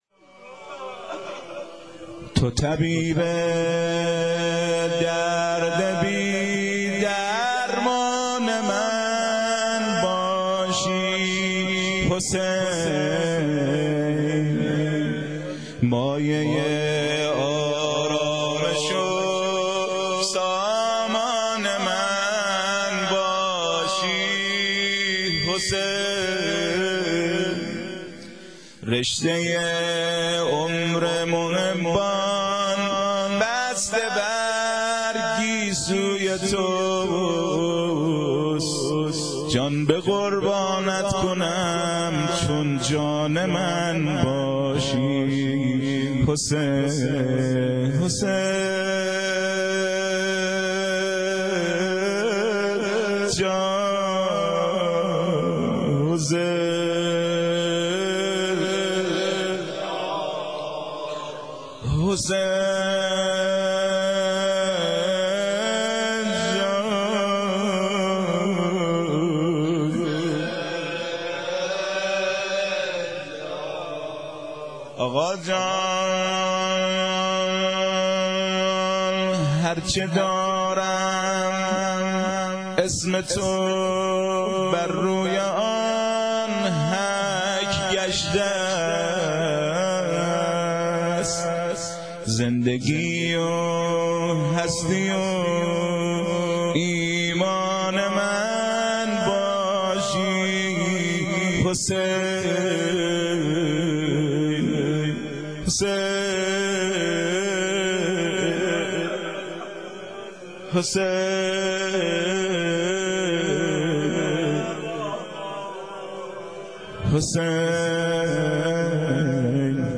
شعرخوانی بخش سوم - شب هفتم محرم 1389